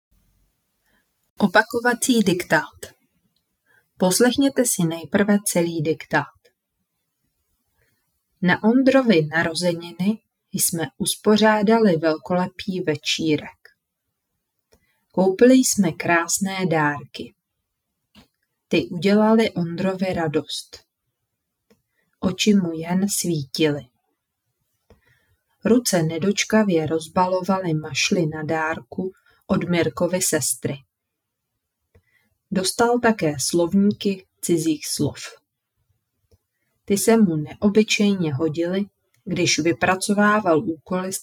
V tomto dílu se zaměřujeme na opakování a upevnění gramatického jevu, který dělá studentům 5. třídy často potíže. Diktáty odpovídají osnovám, zvuky jsou čisté a srozumitelné, tempo vhodné pro děti.